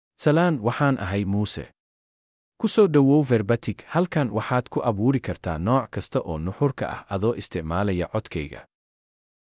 MaleSomali (Somalia)
Muuse is a male AI voice for Somali (Somalia).
Voice sample
Listen to Muuse's male Somali voice.
Muuse delivers clear pronunciation with authentic Somalia Somali intonation, making your content sound professionally produced.